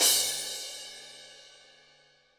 • Cymbal E Key 01.wav
Royality free single cymbal hit tuned to the E note. Loudest frequency: 6984Hz
cymbal-e-key-01-ACK.wav